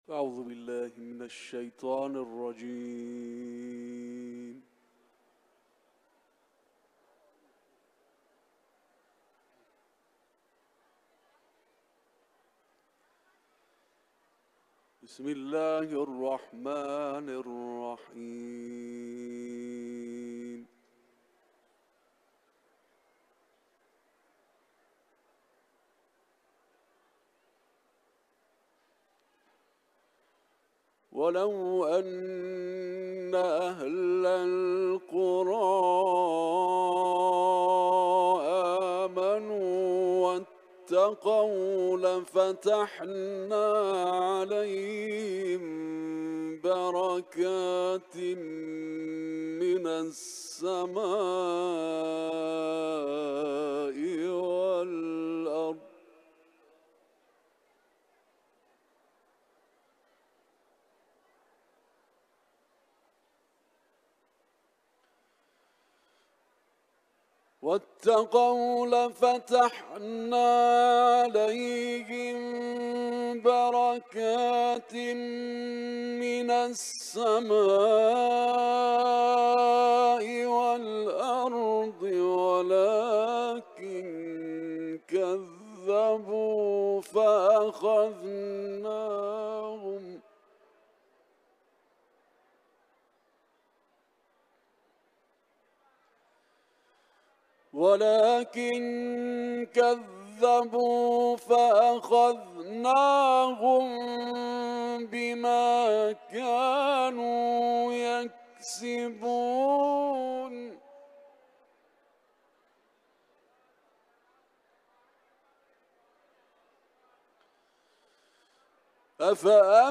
قاری بین‌المللی قرآن از آیات ۹۶ تا ۱۰۴ سوره «اعراف» و نیز آیات سوره «کوثر» که در حرم مطهر رضوی به اجرا رسیده است
حرم مطهر رضوی